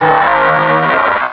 Televersement cris 4G.